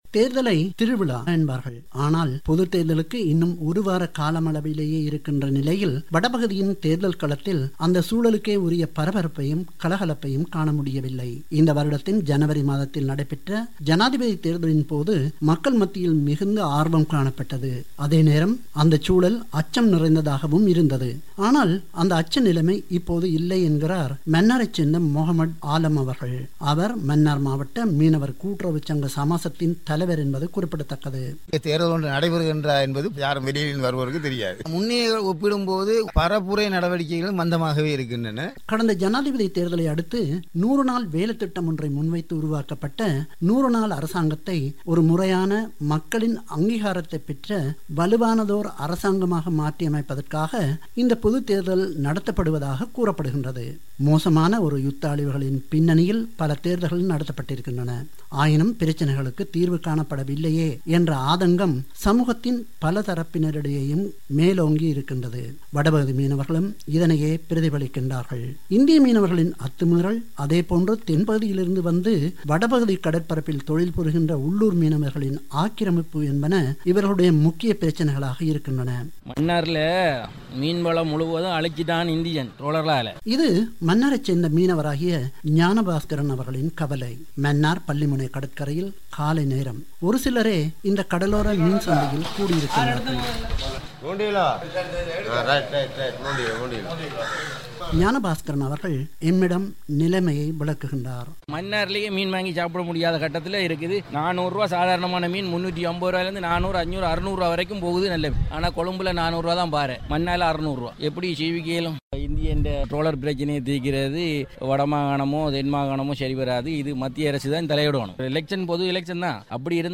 இந்த விடயங்கள் குறித்த அவரது செய்திப் பெட்டகத்தை இங்கு கேட்கலாம்.